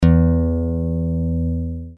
Click on the play button beside each string to use as an audio reference any time you need to tune your guitar.
Low E String